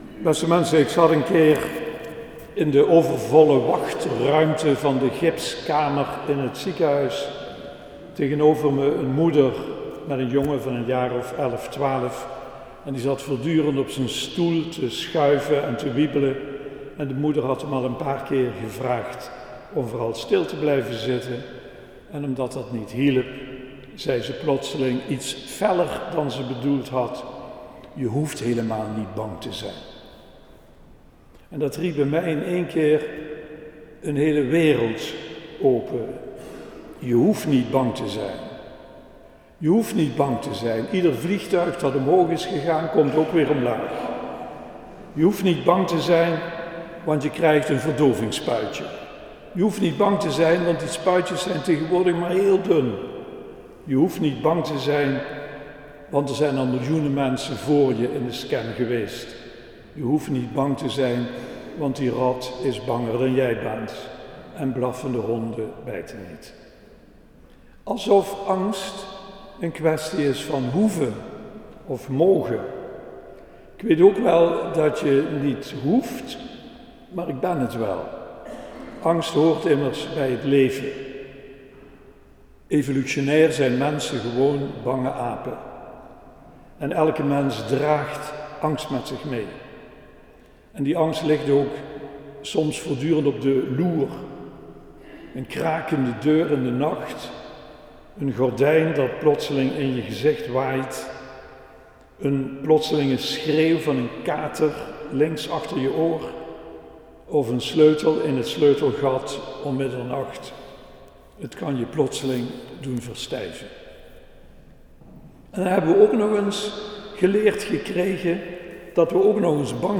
De datum waarop de preek gehouden is ligt gewoonlijk een week later